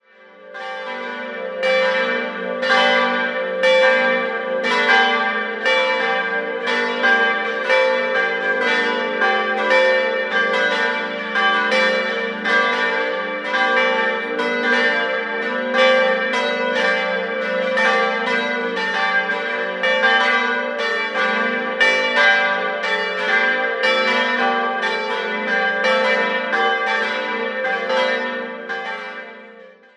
4-stimmiges ausgefülltes G-Dur-Geläute: g'-a'-h'-d'' Die drei größeren Glocken wurden 1948 von der Gießerei Otto in Bremen-Hemelingen gegossen, die kleine stammt aus dem Jahr 1642 von Georg Schelchshorn in Regensburg.